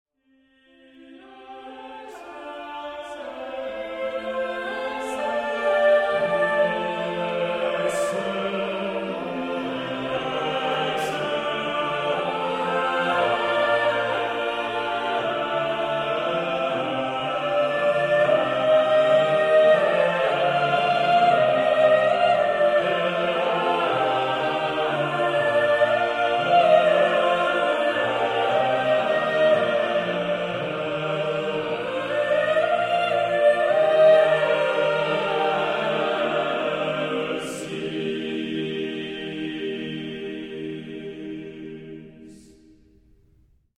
most meditative and serene setting